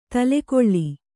♪ tale koḷḷi